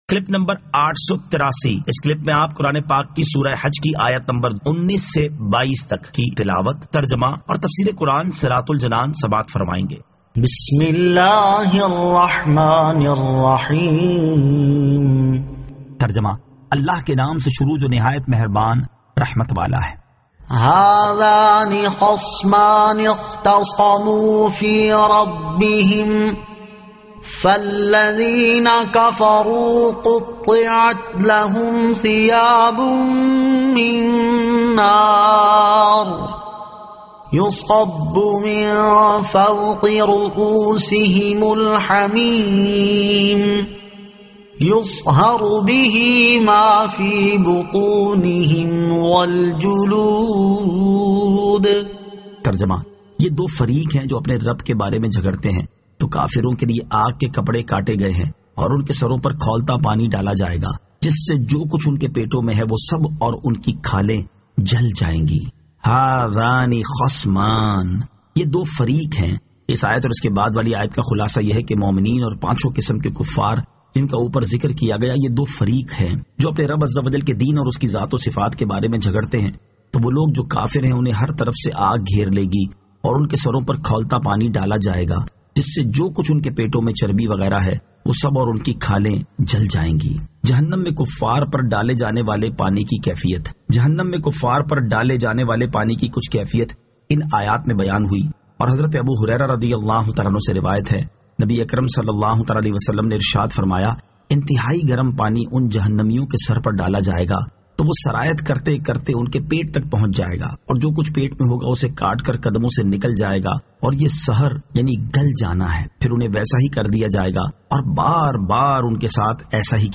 Surah Al-Hajj 19 To 22 Tilawat , Tarjama , Tafseer
2022 MP3 MP4 MP4 Share سُورَۃُ الْحَجِّ آیت 19 تا 22 تلاوت ، ترجمہ ، تفسیر ۔